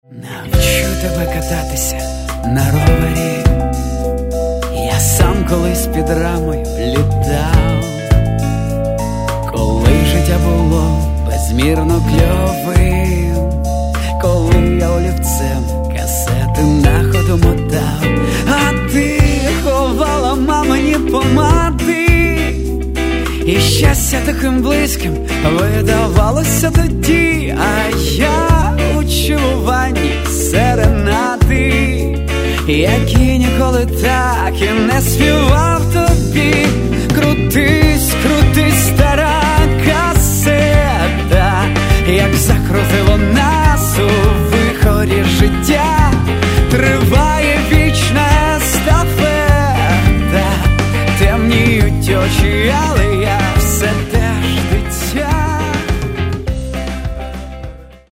Catalogue -> Rock & Alternative -> Simply Rock